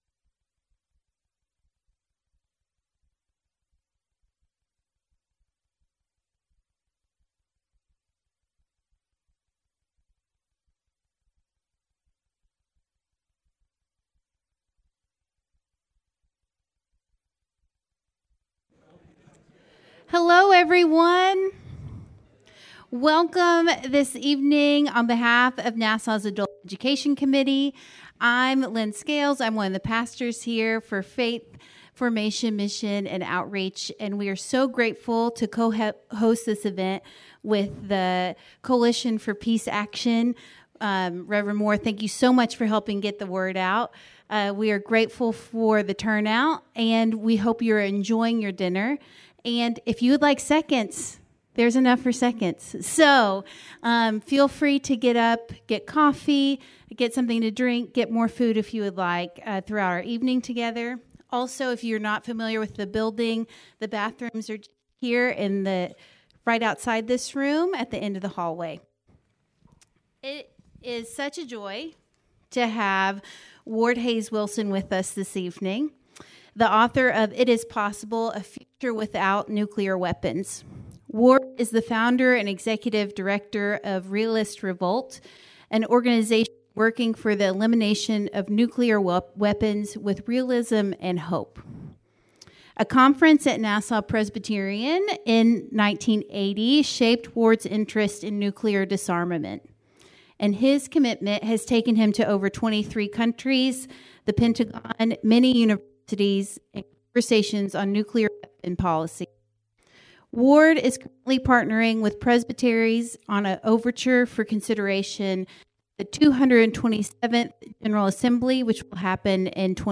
Light supper provided. Hosted by the Adult Education Committee.